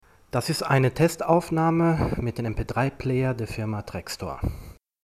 Trekstor: Hörprobe 1 (unbearbeitet)
trekstor-sprachtest.mp3